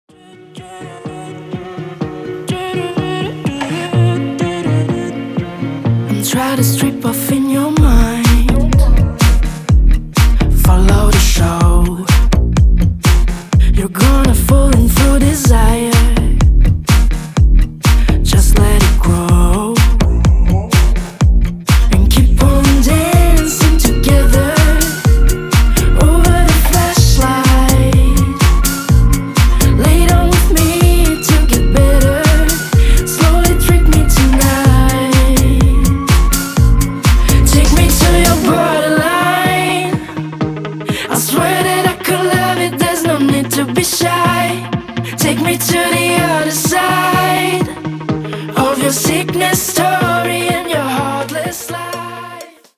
• Качество: 320, Stereo
deep house
красивый женский голос